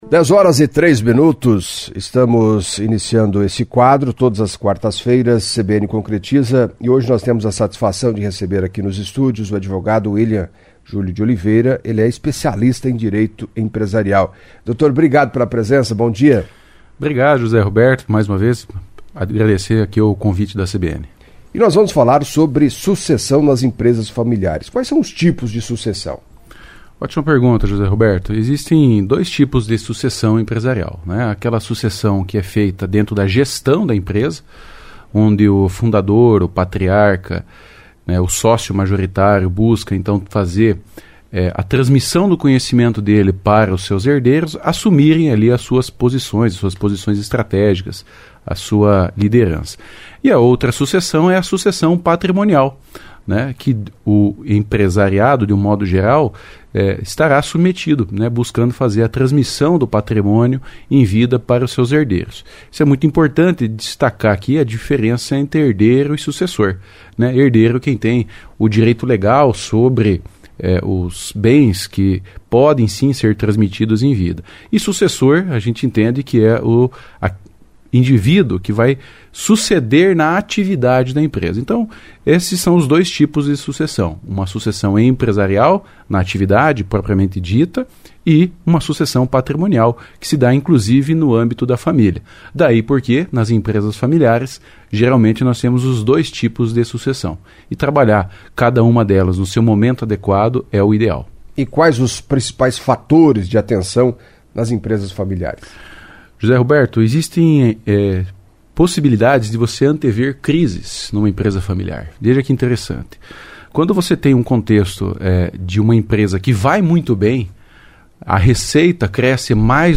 Em entrevista à CBN Cascavel